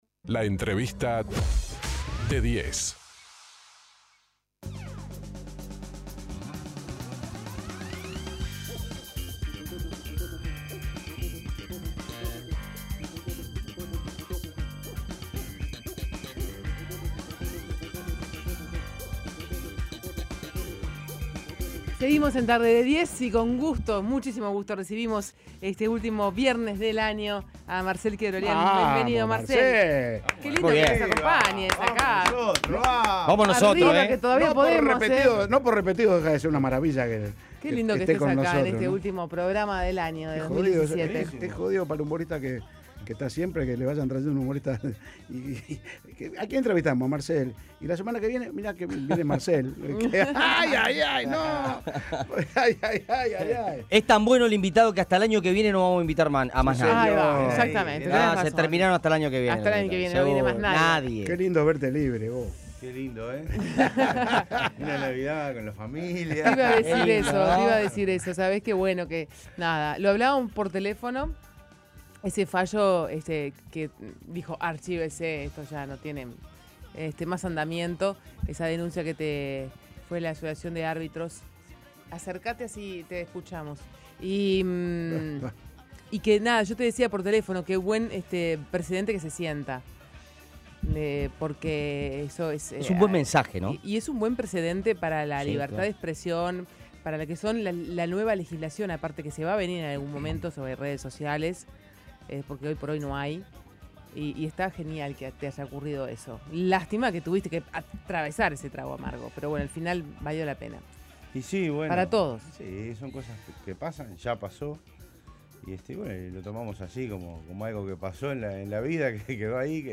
El humorista y cómico Marcel Keoroglian fue entrevistado en el programa La Tarde D10, y comentó su momento personal y analizó la situación que atravesó en la Justicia por haber brindado su punto de vista en redes sociales por los arbitrajes en los encuentros que disputó Rampla Juniors.